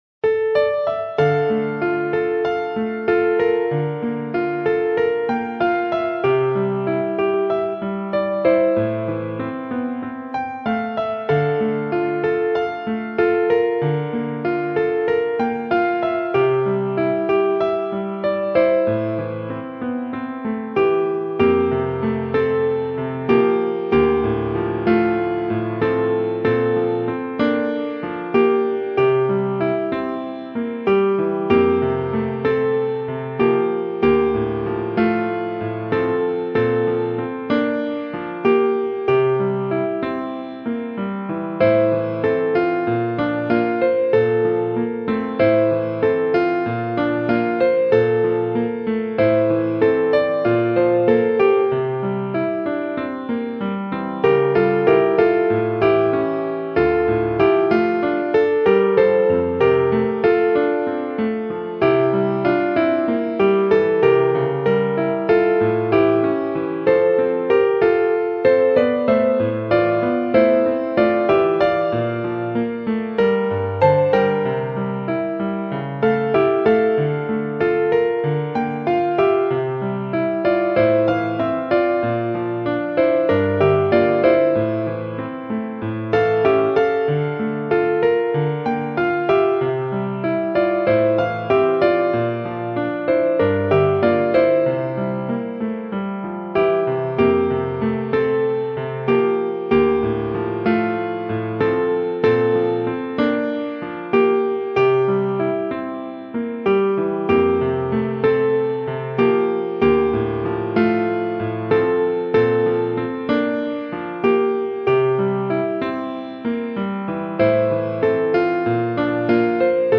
Pianist - Arrangeur
Pianist